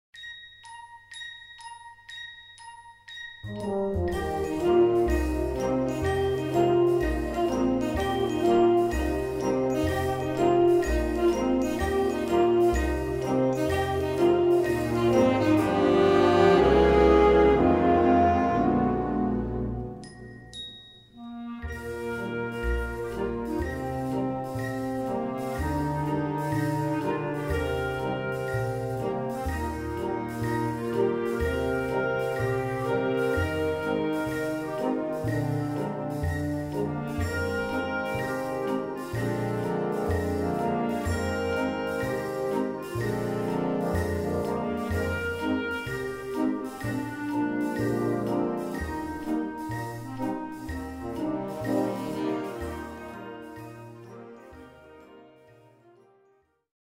A4 Besetzung: Blasorchester Zu hören auf